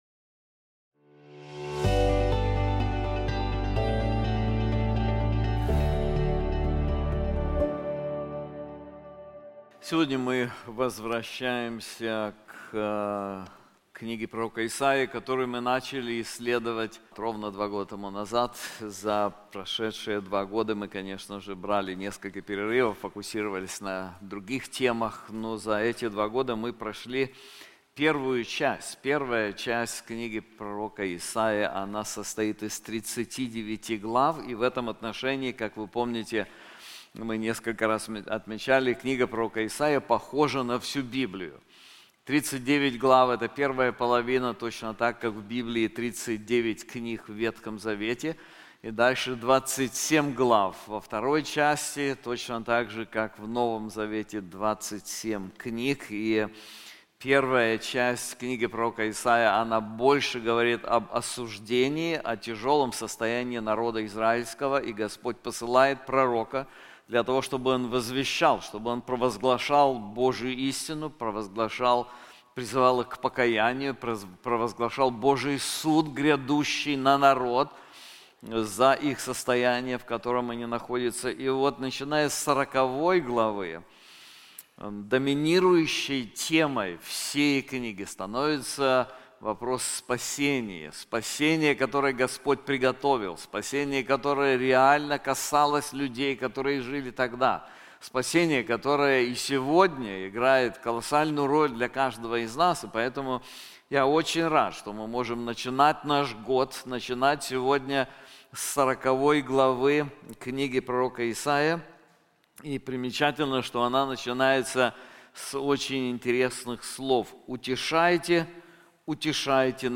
Эта проповедь о Боге и Его утешении.